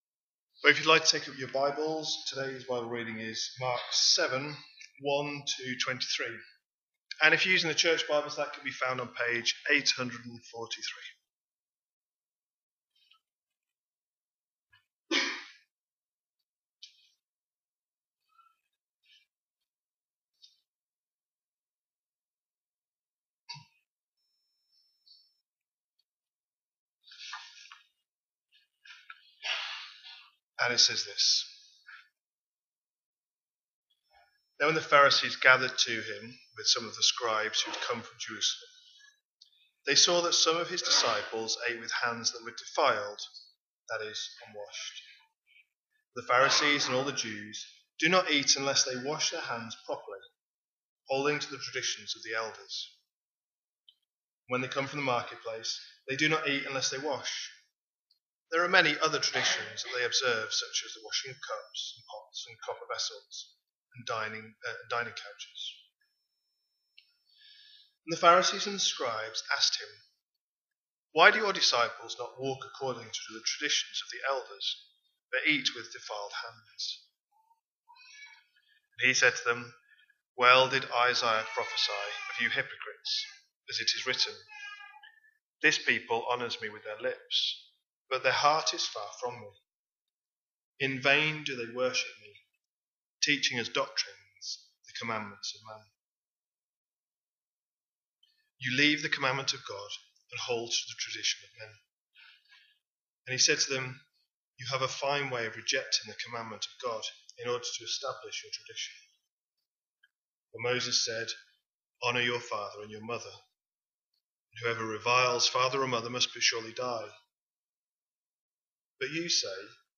A sermon preached on 23rd November, 2025, as part of our Mark 25/26 series.